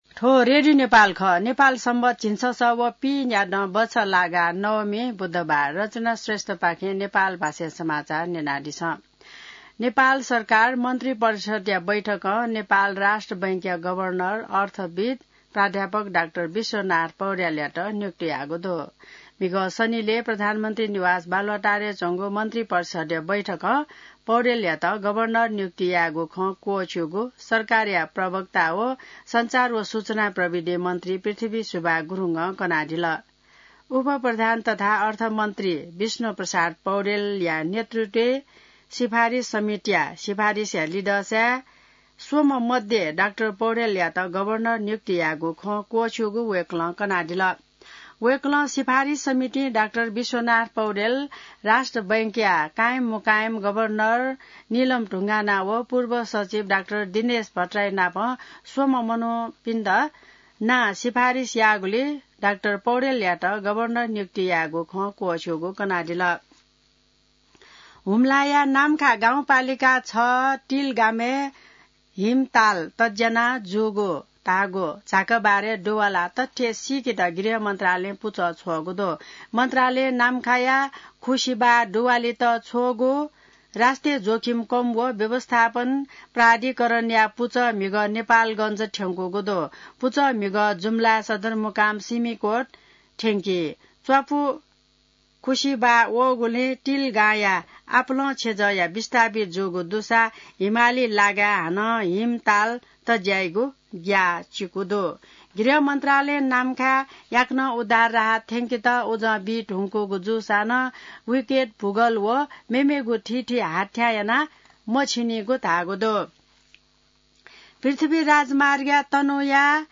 नेपाल भाषामा समाचार : ७ जेठ , २०८२